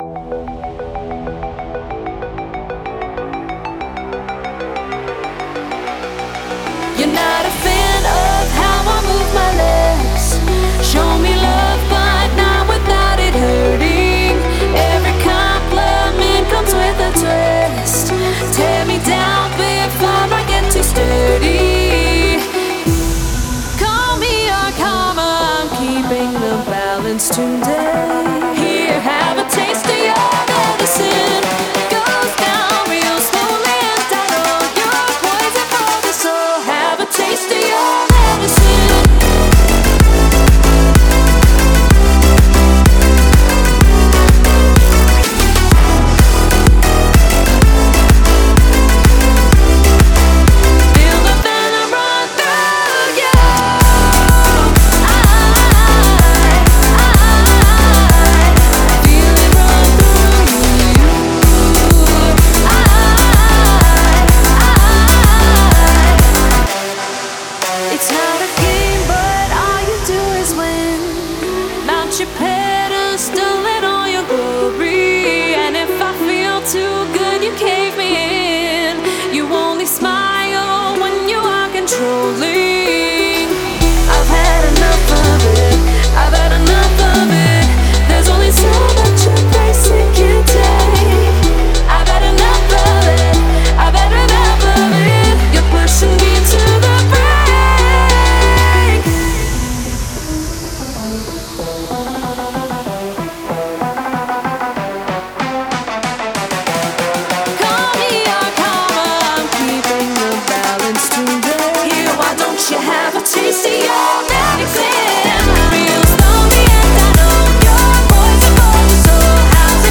это динамичная электронная композиция в жанре EDM